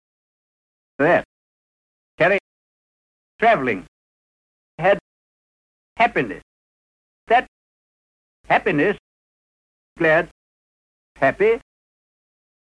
All the recordings have been subjected to lossy MP3 compression at some time during their lives.
Listen to open TRAP (650-800Hz) by Robert Baden-Powell (Figure 1):